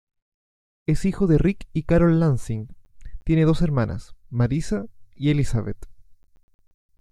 Pronounced as (IPA) /eɾˈmanas/